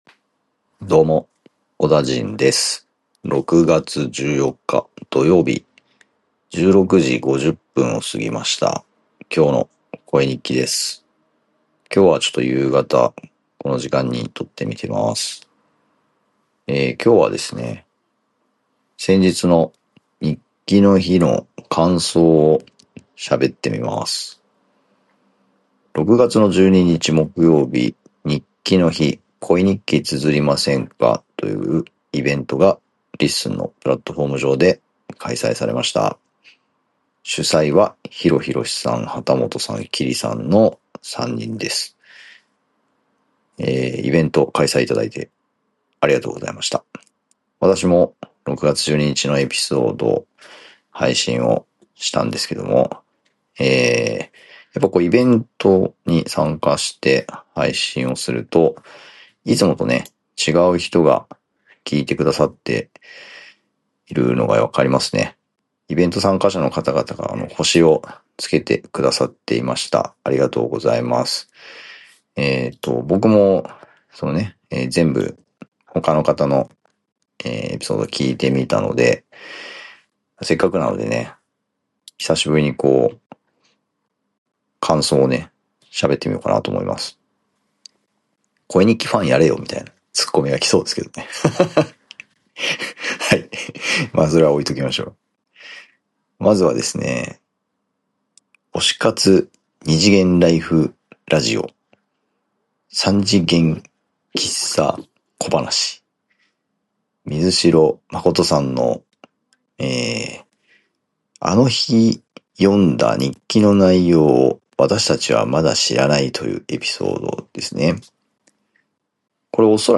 だらだらと感想を話しました。